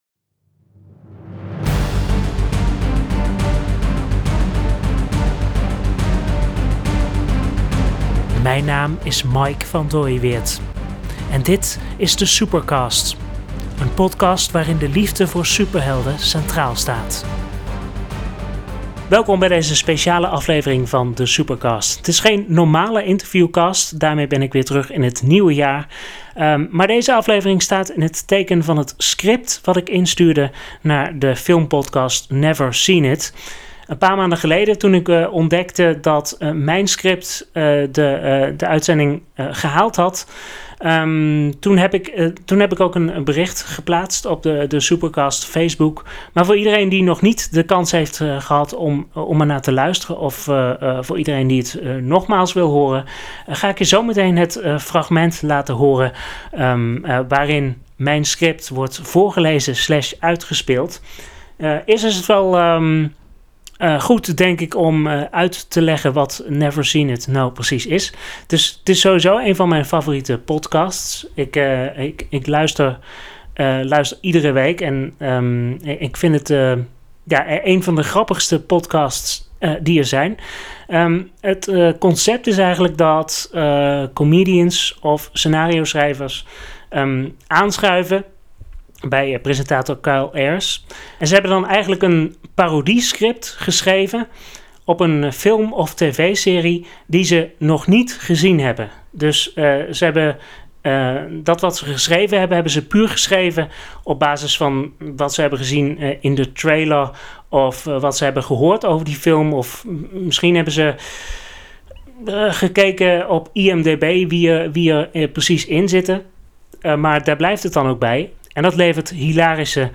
Een aan aantal maanden geleden haalde mijn Fantastic Four-script de fanscript-aflevering van de podcast Never Seen It. Speciaal voor iedereen die er nog niet naar heeft kunnen luisteren, of die het graag nog eens wil horen, laat ik je in deze podcast het fragment horen waarin mijn script wordt voorgelezen/uitgespeeld.